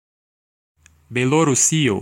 Ääntäminen
UK : IPA : /ˌbɛləˈɹuːs/ US : IPA : /ˌbɛləˈɹus/